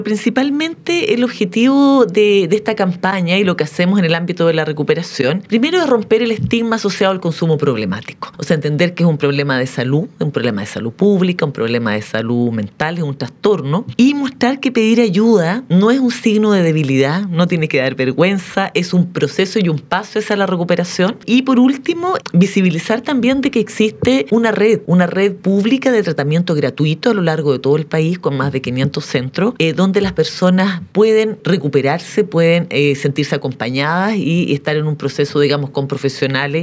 En conversación con Radios Regionales, la directora nacional del SENDA, Natalia Riffo, señaló cuál es el mensaje principal que busca transmitir esta segunda temporada de la audioserie.
extracto_entrevista_1.mp3